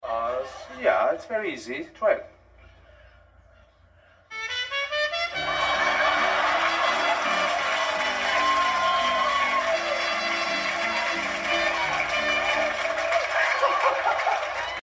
Carlos Mencia blows a horn and Mexican music comes out